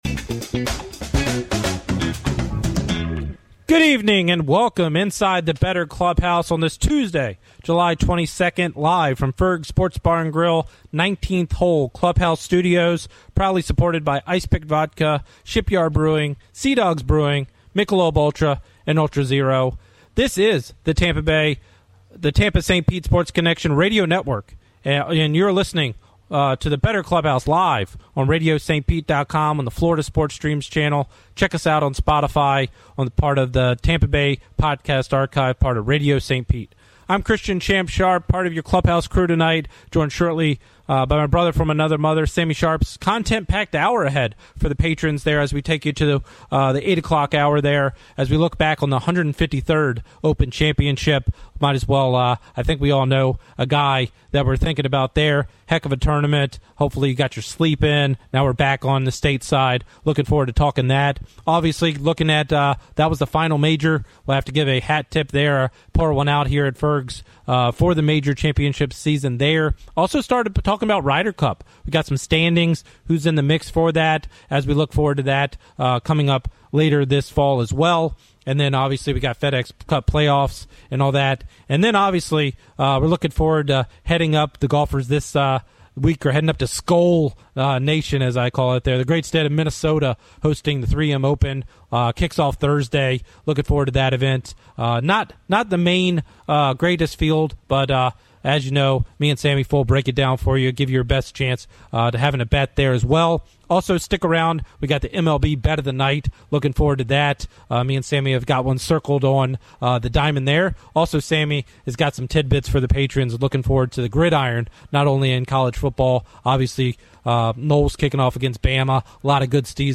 The Bettor's Clubhouse 7-22-25; Live from Ferg's! Airs on Florida Sports Stream Channel Tuesdays 7pm